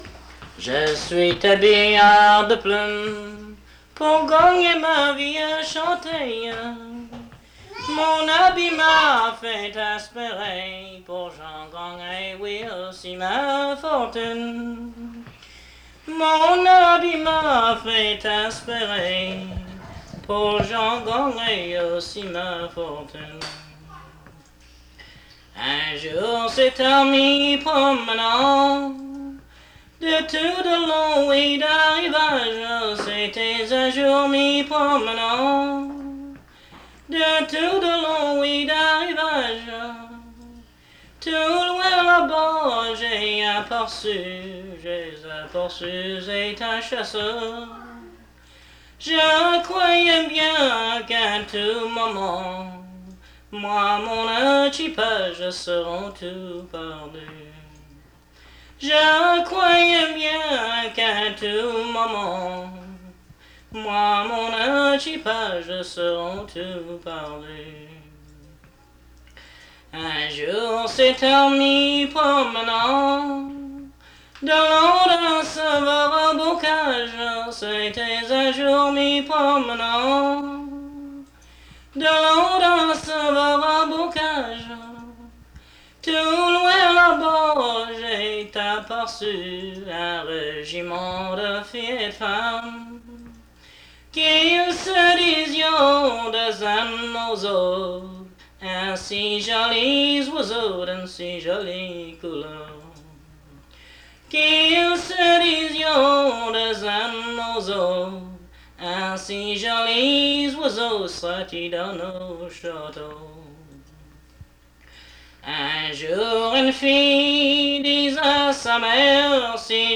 Chanson Item Type Metadata
Emplacement Cap St-Georges